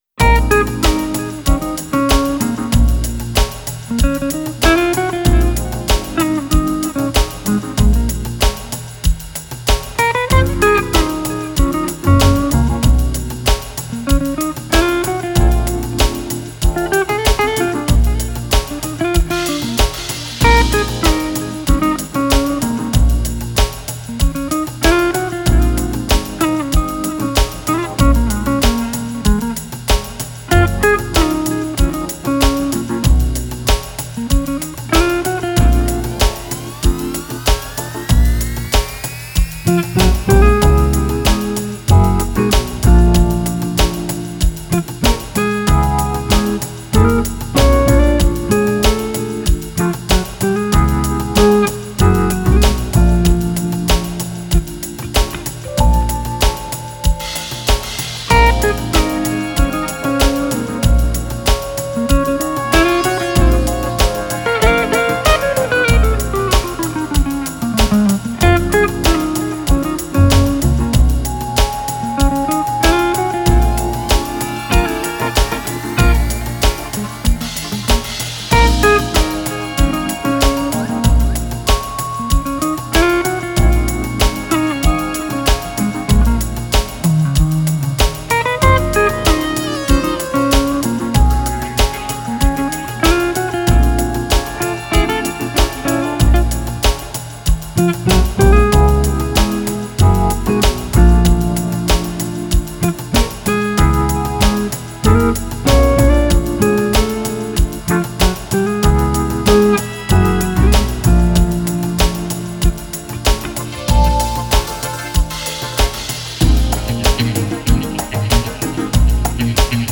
Жанр: Electronic, Lounge, Chill Out, Downtempo